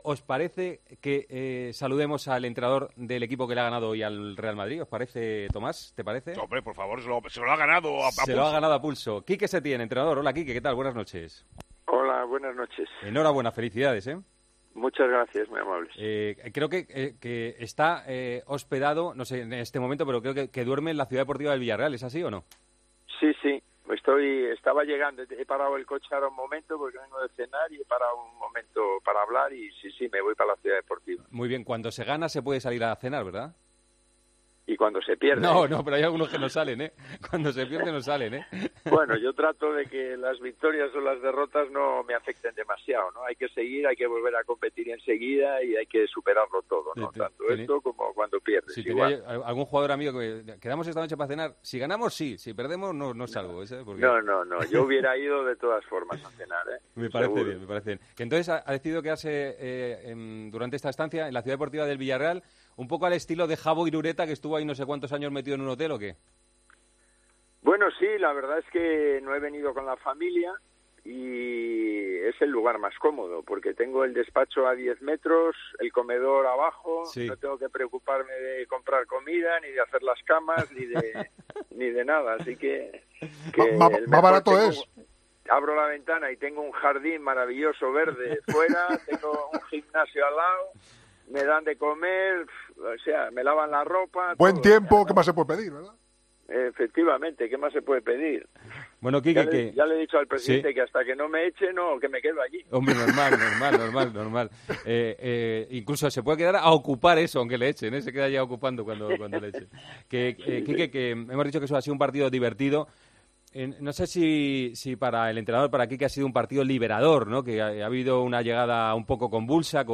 Horas más tarde del triunfo del 'submarino amarillo', su técnico, Quique Setién, atendió a los micrófonos de 'Tiempo de Juego' donde analizó ese partido y los polémicos penaltis por las manos de Foyth y de Alaba.